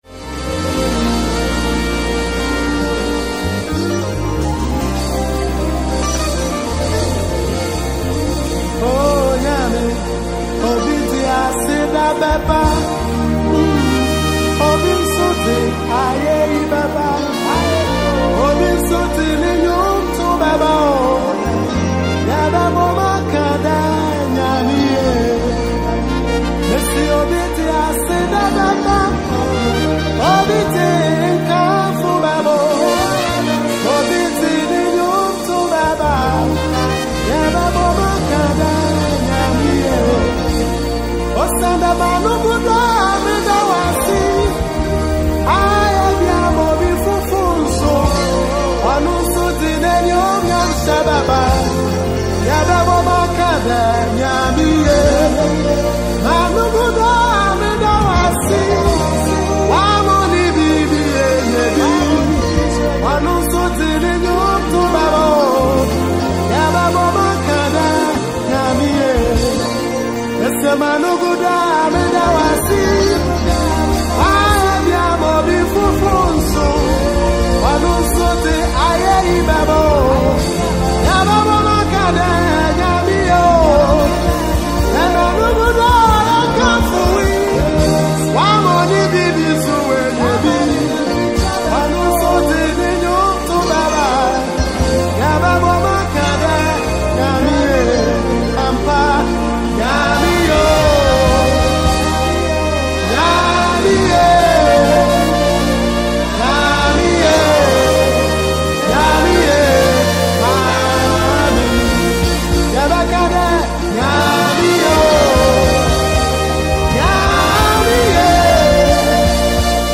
Mp3 Download Gospel Song.
worship ghana gospel song